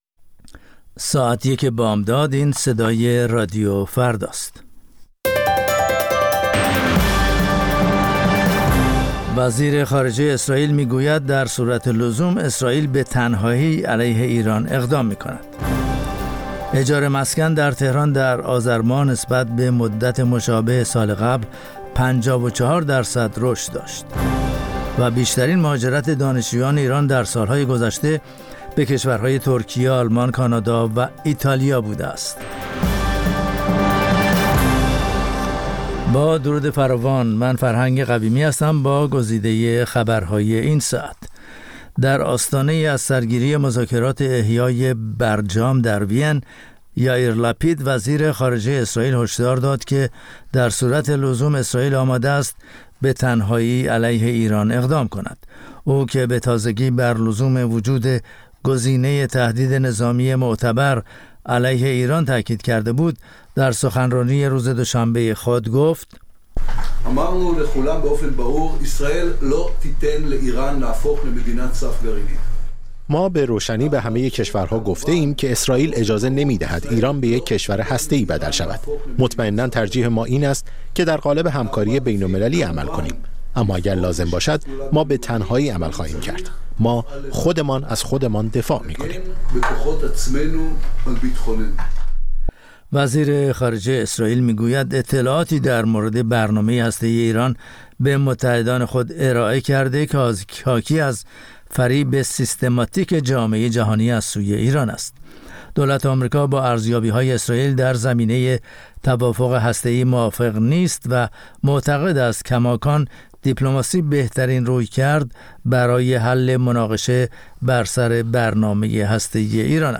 سرخط خبرها ۱:۰۰